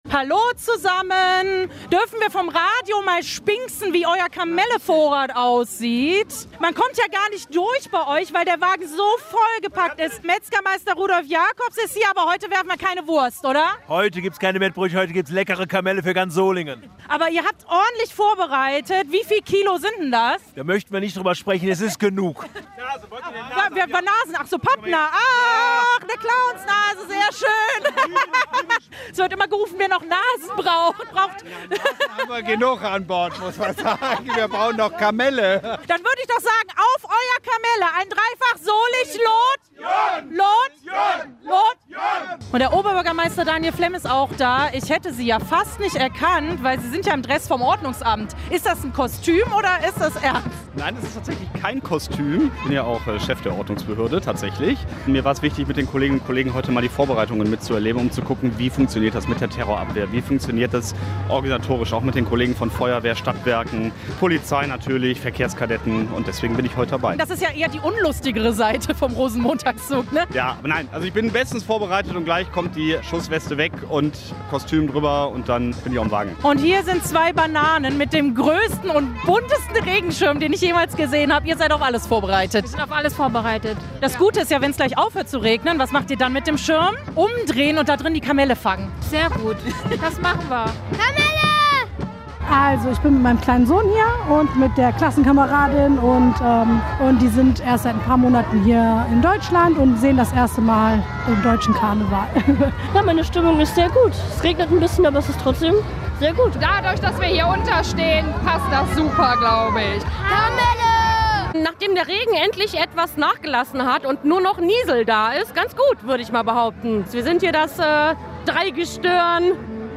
Wir haben die Stimmung in Lennep und Solingen hier für euch in Bild und Ton eingefangen.
Trotz strömendem Regen standen auch in der Solinger Innenstadt viele Menschen an der Zugstrecke, um auf Kamellejagd zu gehen.
Radio RSGRosenmontagszug 2026 in Solingen
romo_collage_2026_solingen.mp3